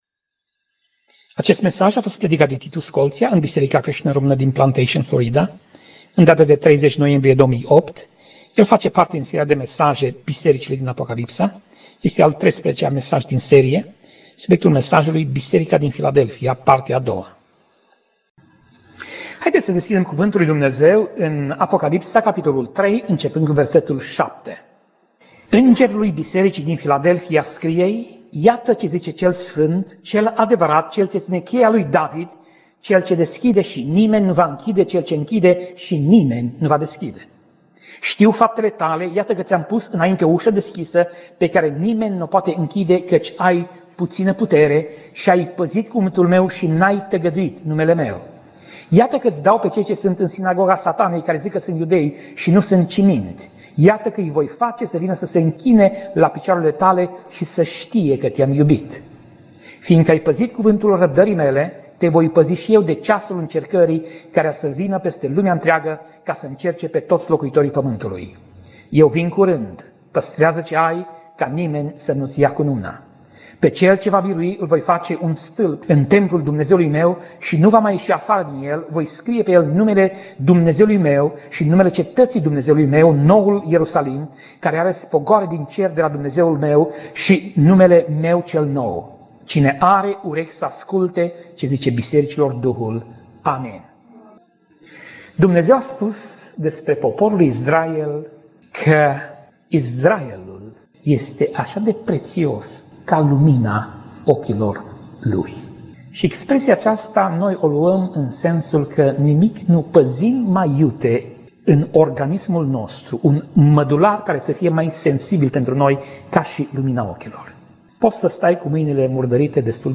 Pasaj Biblie: Apocalipsa 3:7 - Apocalipsa 3:13 Tip Mesaj: Predica